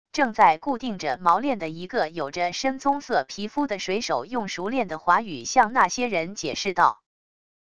正在固定着锚链的一个有着深棕色皮肤的水手用熟练的华语向那些人解释道wav音频生成系统WAV Audio Player